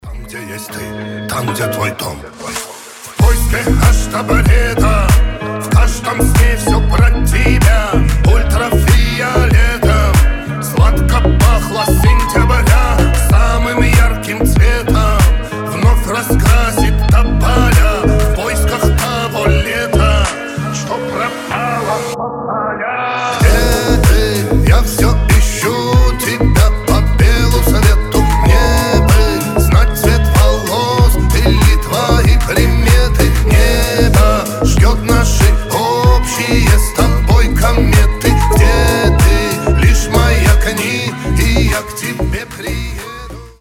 рэп , хип-хоп , ремиксы